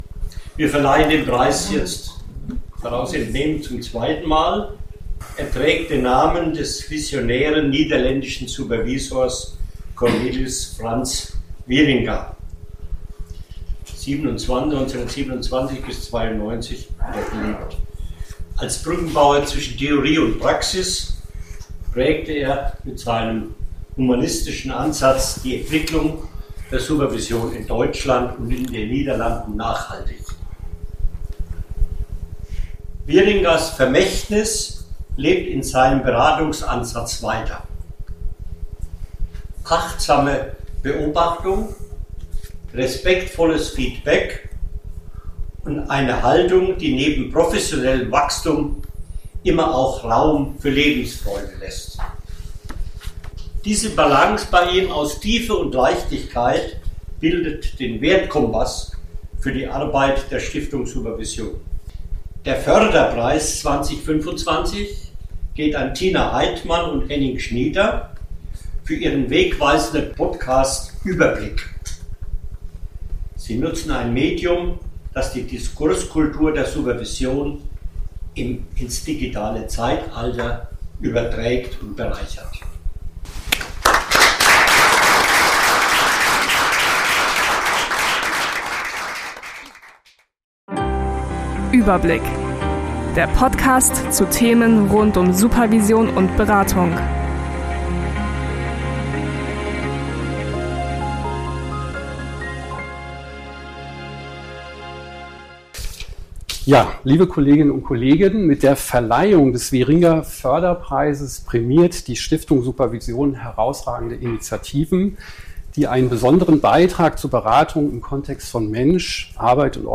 Das Publikum vor Ort hat die Möglichkeit sich interaktiv zu beteiligen und nutzt diese auch ausgiebig.